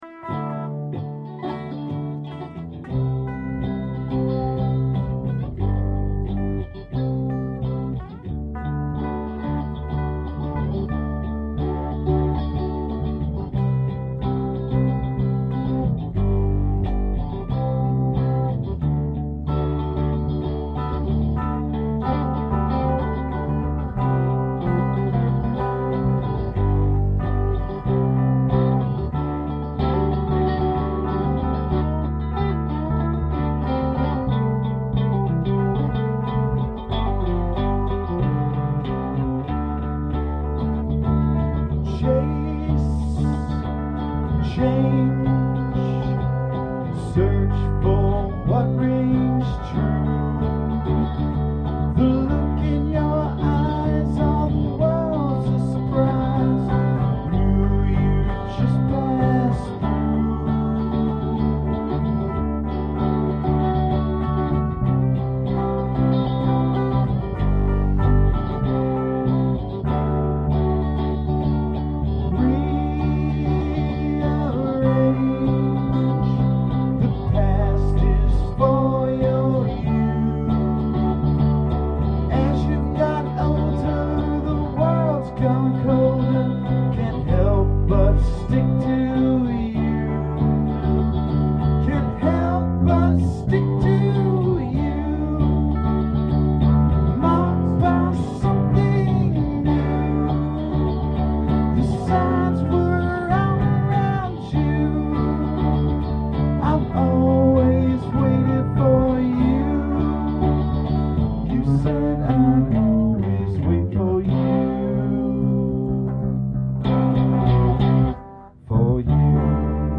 Acoustic Tracks